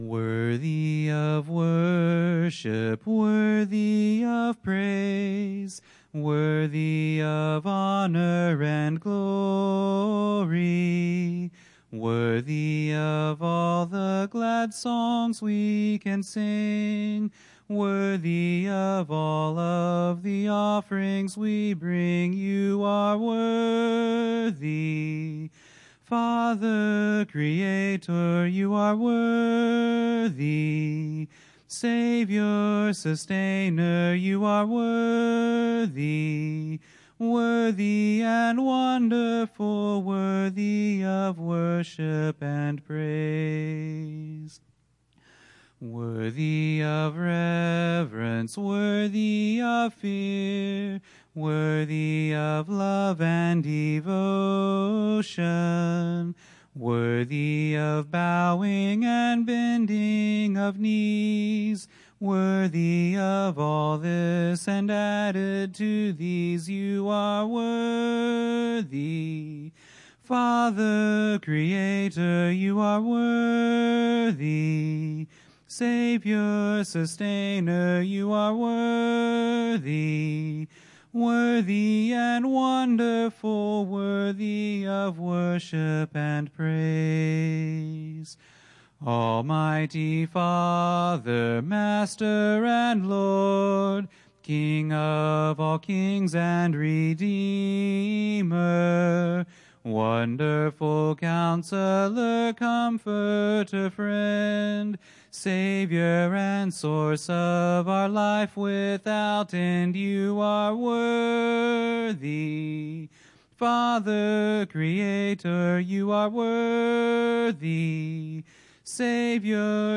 Bible Text: Matthew 1:1-17 | Preacher